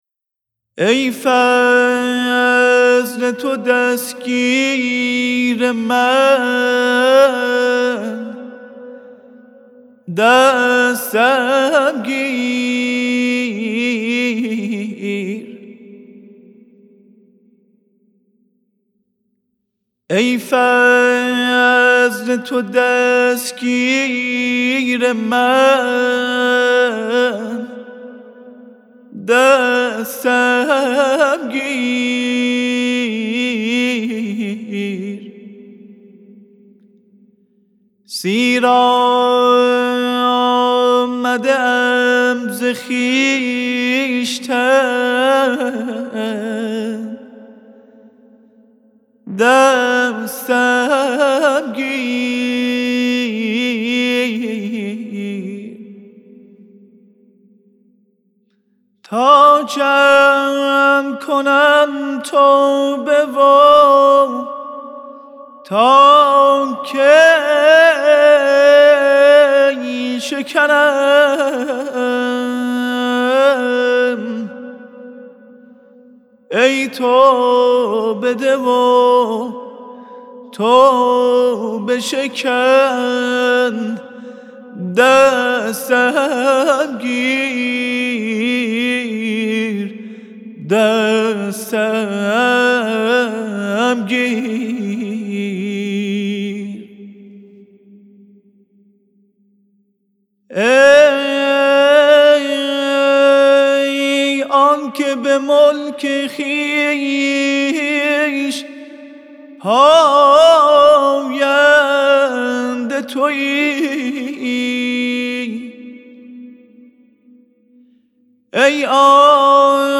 برگرفته از ردیف آوازی موسیقی ایرانی